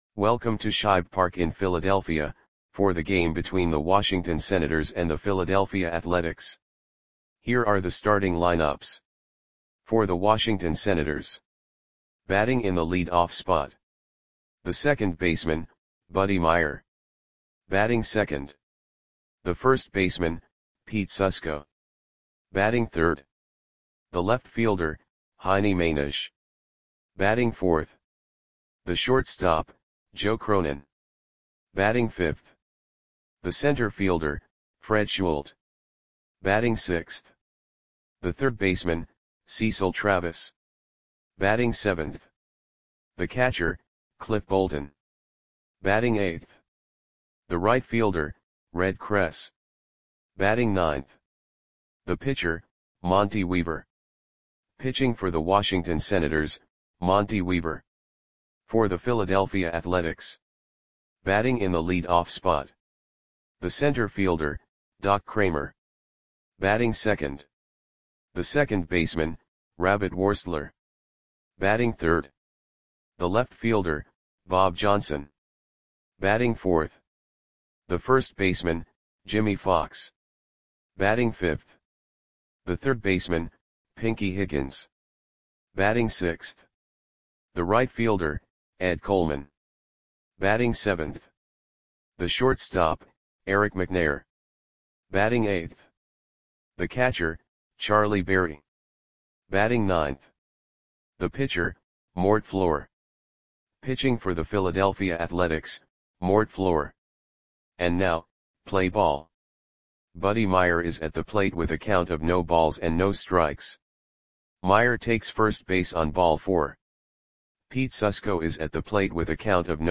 Click the button below to listen to the audio play-by-play.